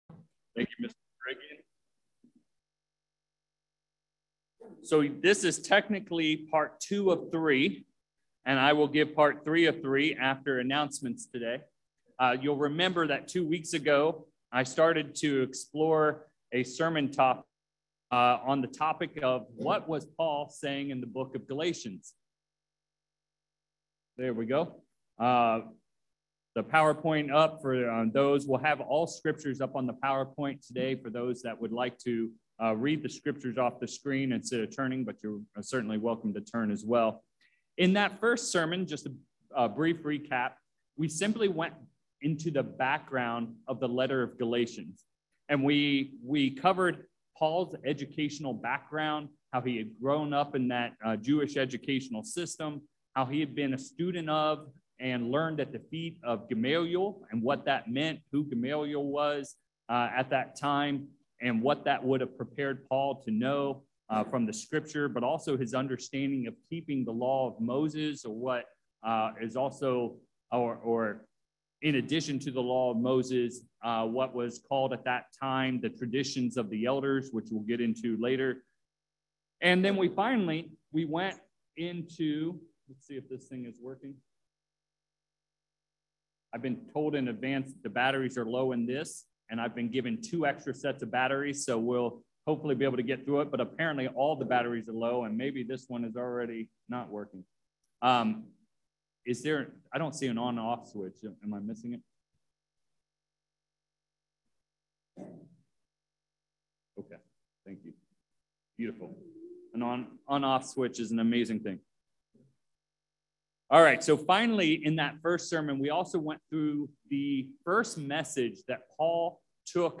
9/24/22 In part one of this series, we dove into the background of the book of Galatians. In this second part of this three-part sermon, we cover chapters 1 and 2 of the book of Galatians, including the topics of 1) What is Paul's real Apostle? 2) What theme is Paul addressing throughout the book? 3) What were the Traditions of the Elders/Fathers & 4) What were the Works of the Law?